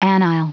Prononciation du mot anile en anglais (fichier audio)
Prononciation du mot : anile